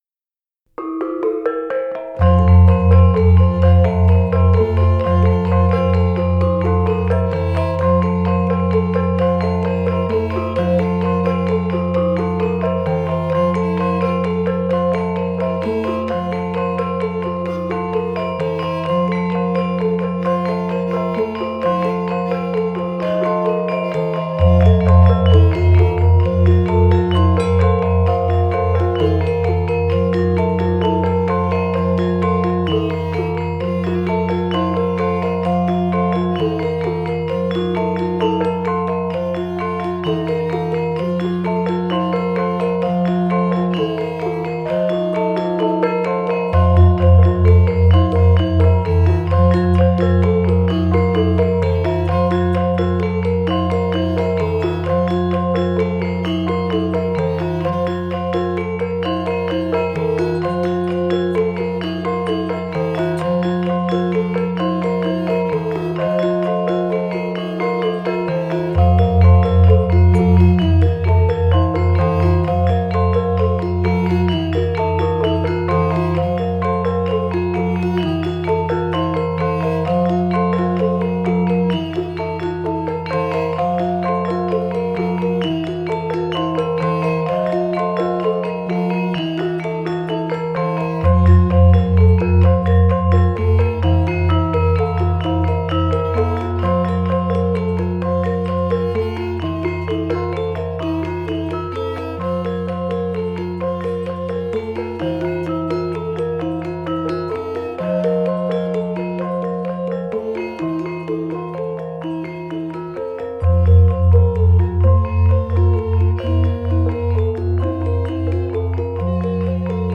is a dynamic process piece
contemporary classical / world music